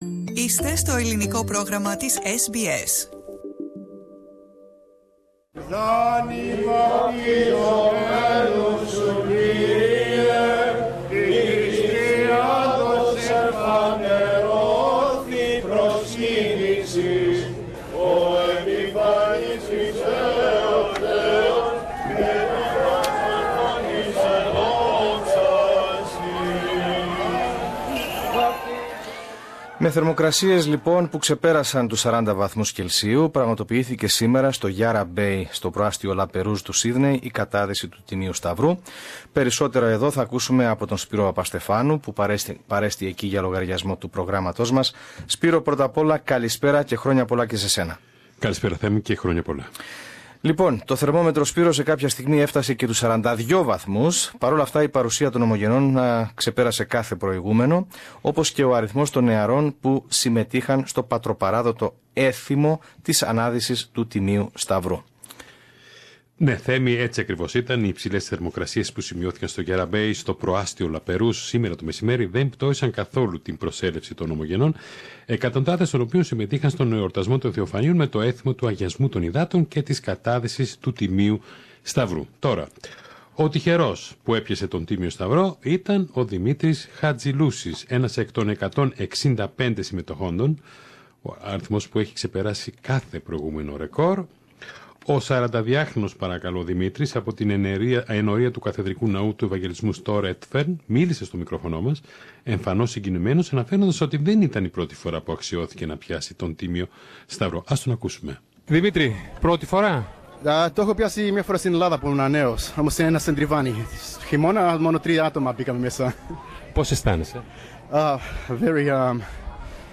Blessing of water in Sydney: Epiphany 2018
The blessing of the water in Sydney's Yarra Bay (La Perouse) Source: SBS Greek